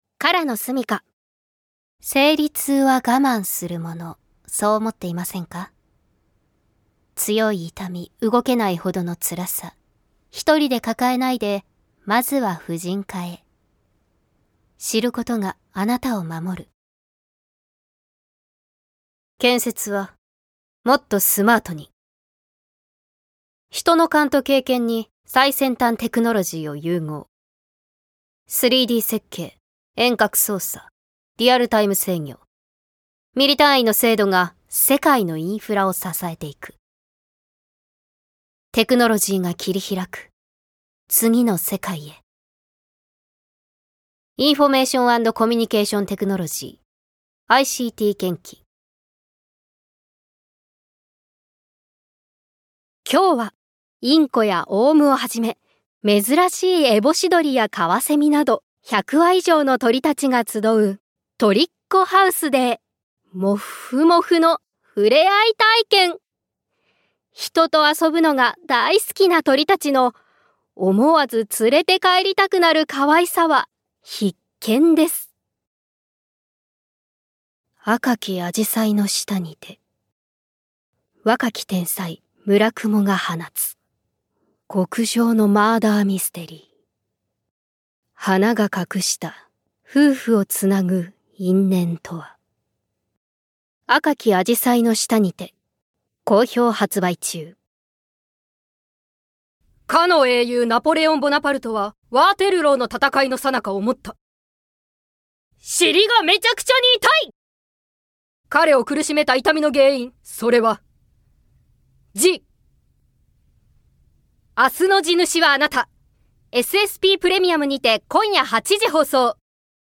◆ナレーション